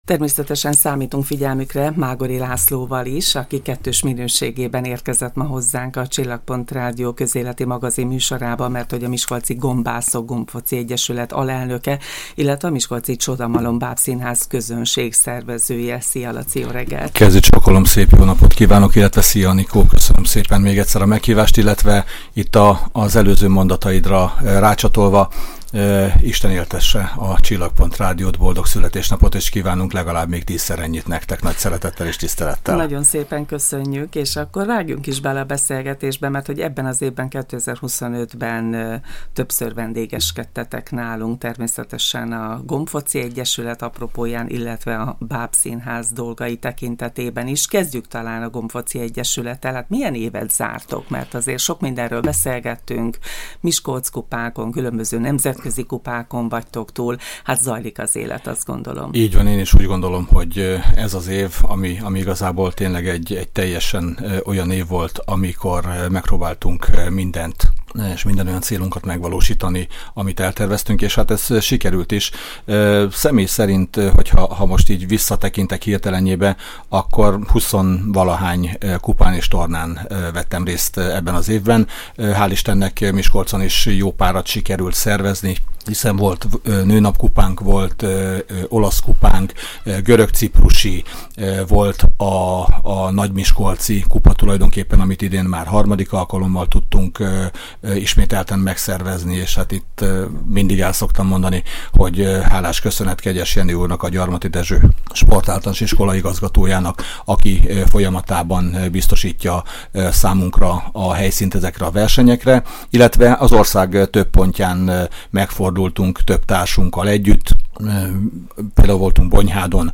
Évzáró beszélgetés